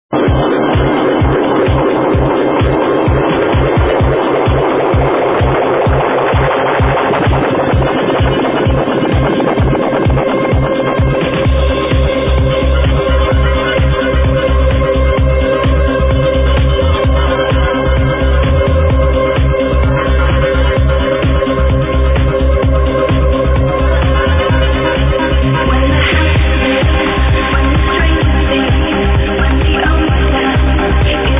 alright...this is from the WMC 2002.